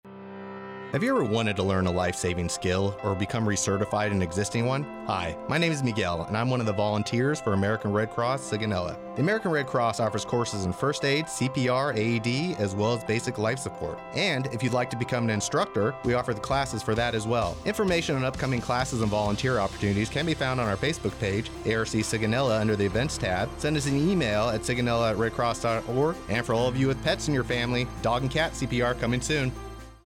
NAVAL AIR STATION SIGONELLA, Italy (Aug. 26 , 2024) Radio spot promotes how to sign up for instructor courses provided by the American Red Cross Sigonella.